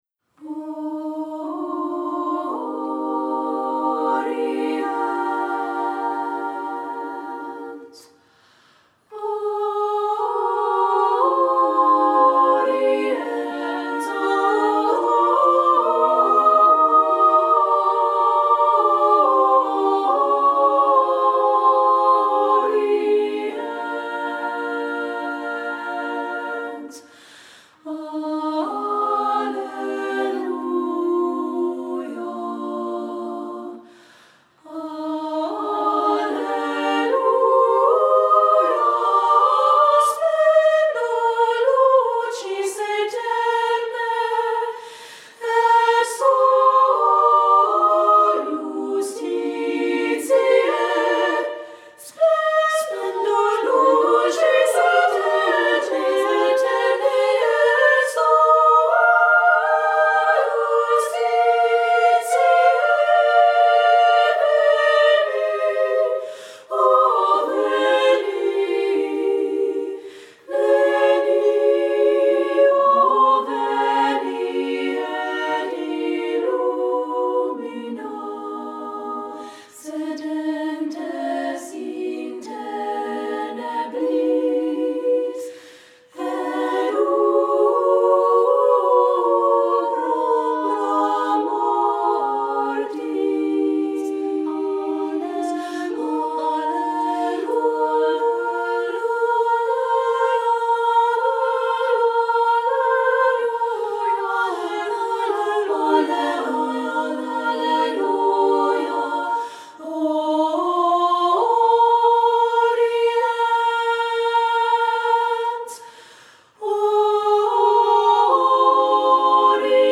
Voicing: SSA(A) a cappella